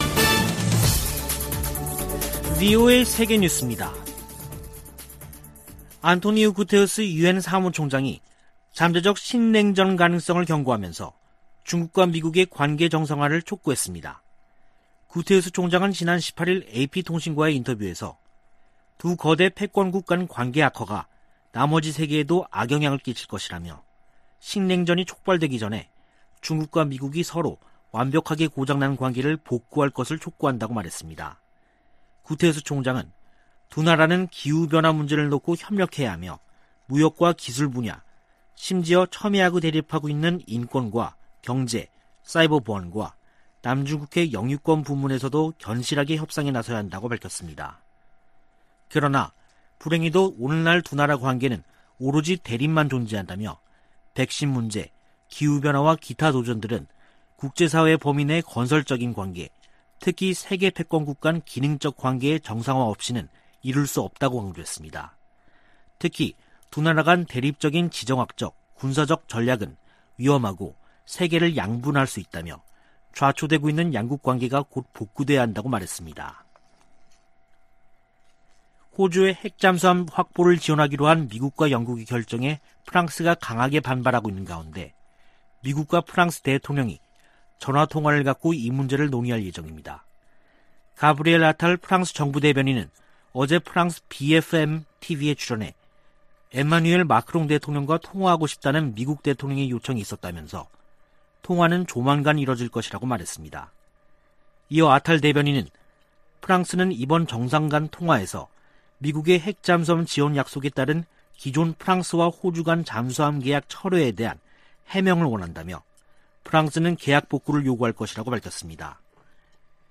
VOA 한국어 간판 뉴스 프로그램 '뉴스 투데이', 2021년 9월 20일 2부 방송입니다. 유엔주재 미국 대사가 북한의 최근 미사일 발사 관련 사안을 대북제재위원회에서도 논의할 것이라고 밝혔습니다. 존 하이튼 미 합참의장은 북한이 미사일 역량을 빠른 속도로 발전시켰다고 17일 말했습니다. 열차를 이용한 북한의 탄도미사일 시험발사는 선제적 대응을 어렵게할 것이라고 미국의 군사 전문가들이 분석했습니다.